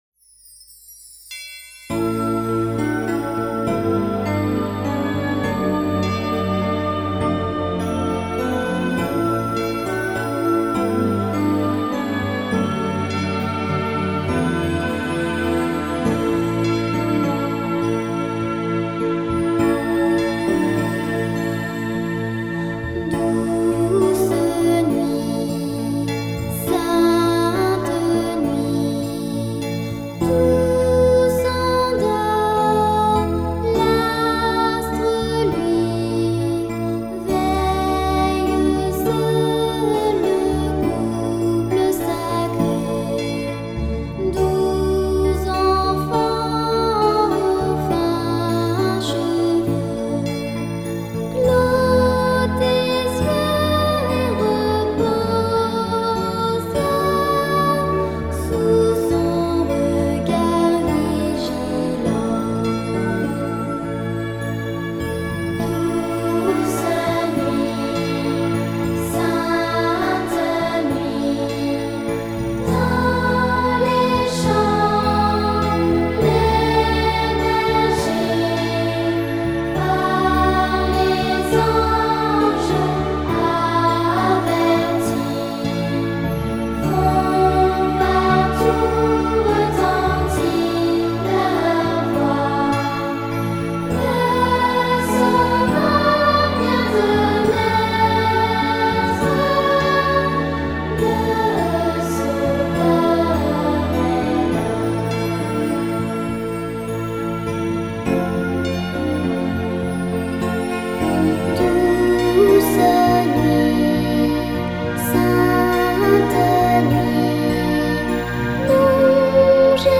音樂類型：西洋音樂
法文聖誕歌曲童聲版 帶你到巴黎過Christmas